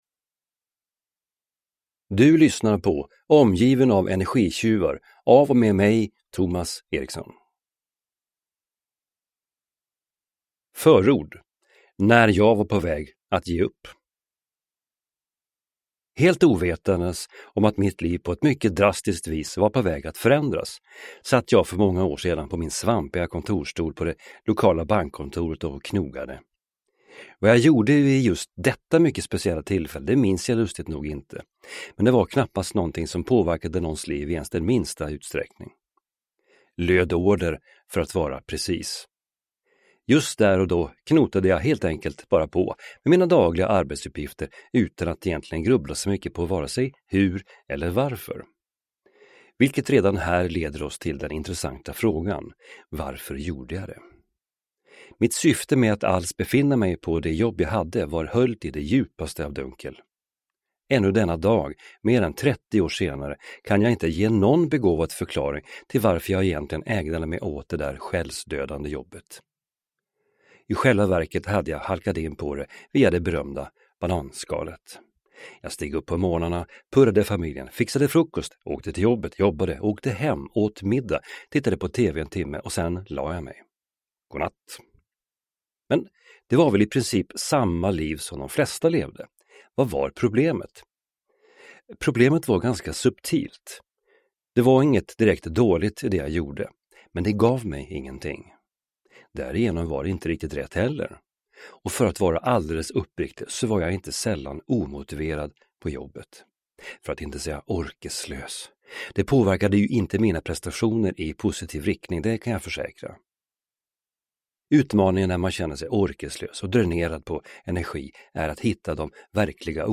Omgiven av energitjuvar (ljudbok) av Thomas Erikson